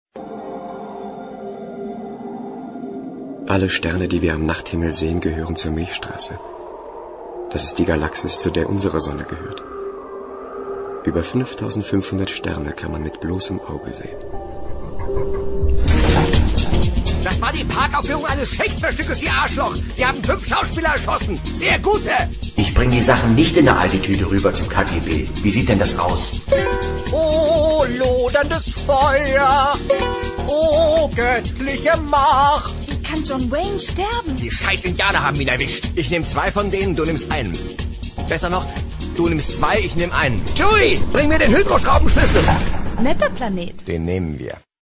AUDIODEMO: Kleinere, ältere Audioschnipsel